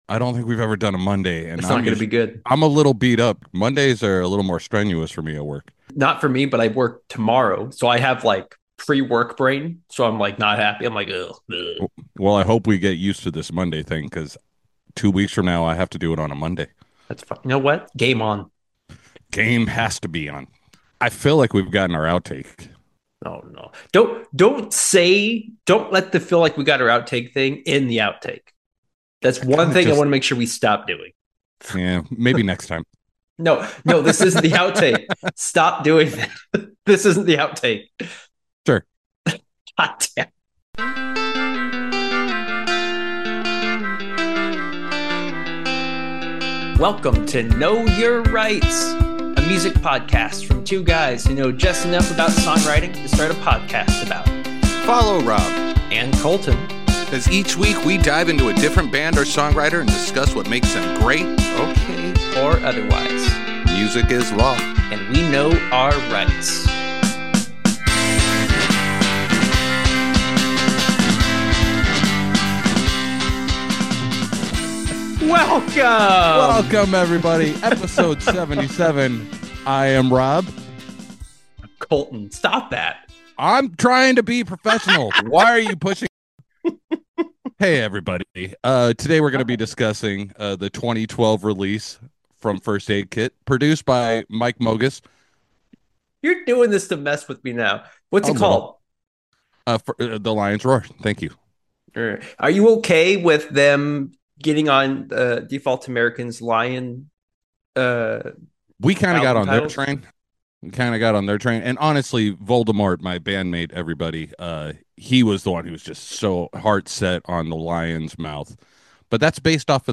This week, our hosts discuss The Lion's Roar by Swedish folk duo First Aid Kit.